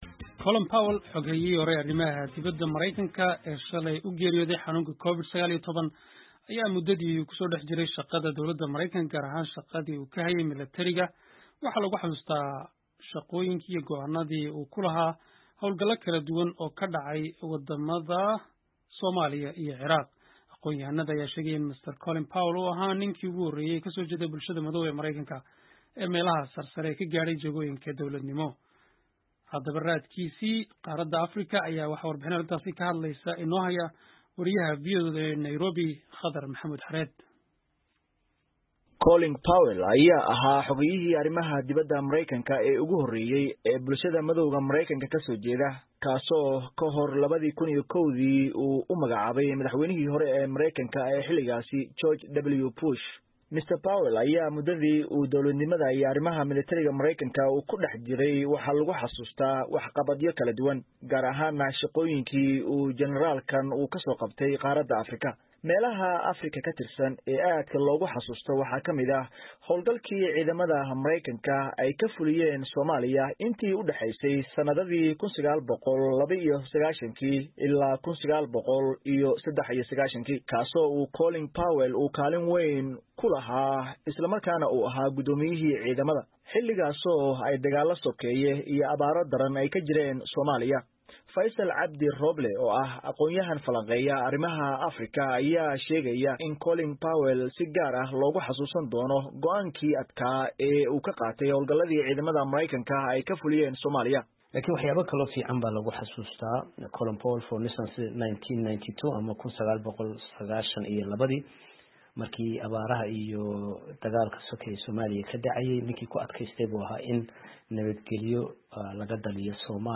WASHINGTON —